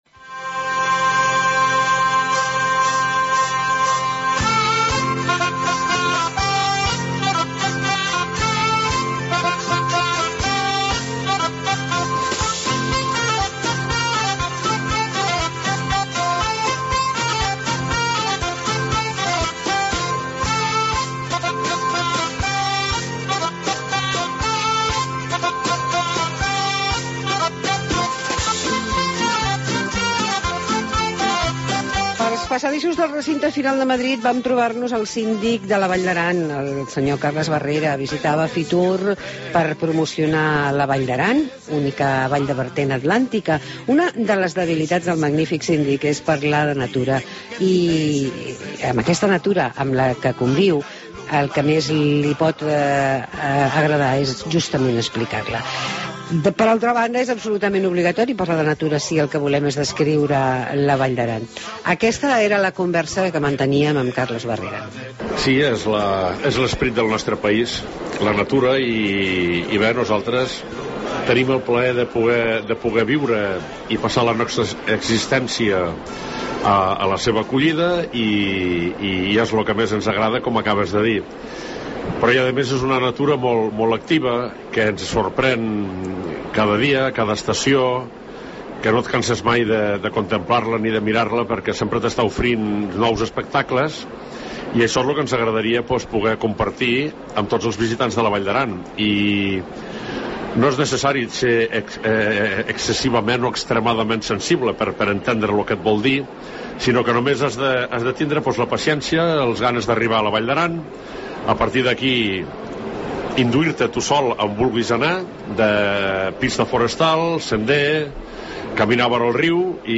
Entrevista a Carles Barrera, Síndic de la Val d'Aràn